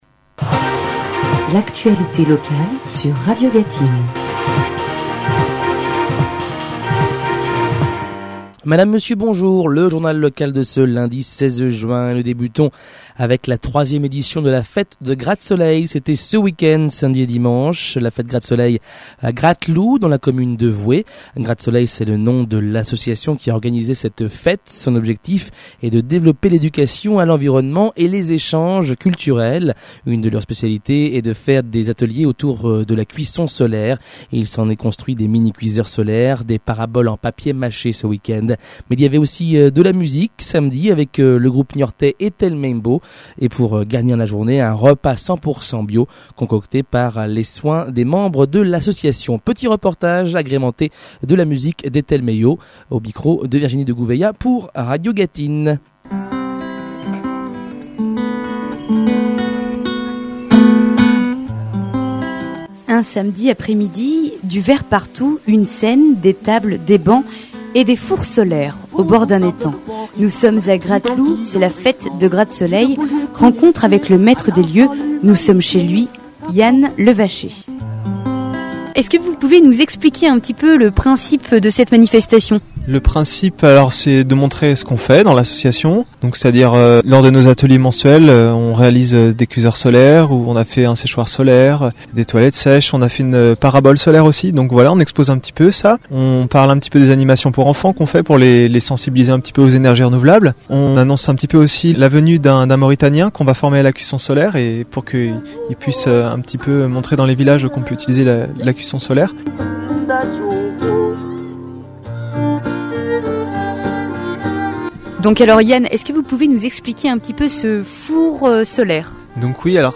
Reportage de Radio G�tine F�te annuelle - Grattesoleil
Reportage de Radio G�tine